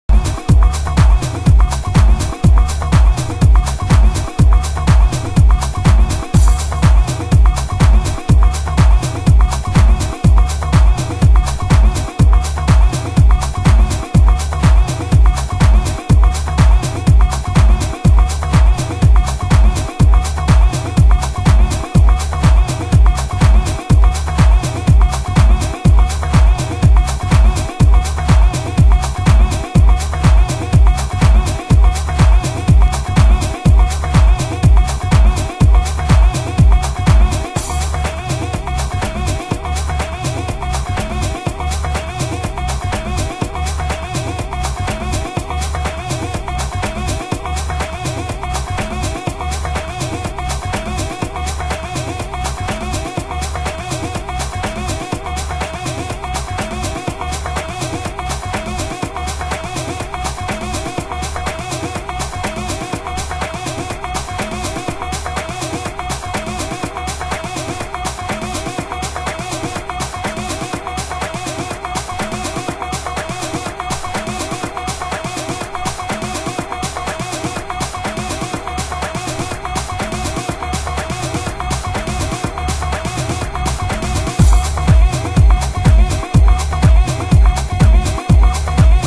盤質：Aイントロにプレスノイズ （不純物）